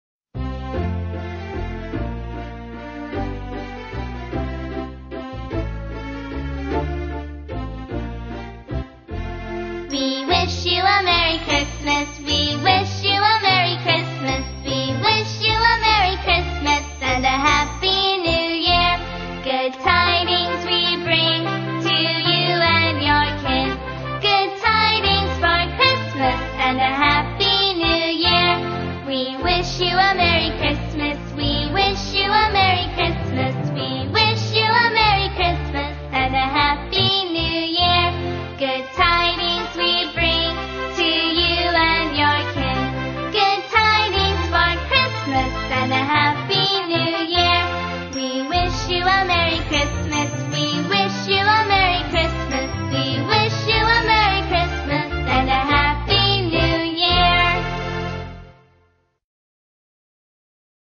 在线英语听力室英语儿歌274首 第247期:We Wish You a Merry Christmas的听力文件下载,收录了274首发音地道纯正，音乐节奏活泼动人的英文儿歌，从小培养对英语的爱好，为以后萌娃学习更多的英语知识，打下坚实的基础。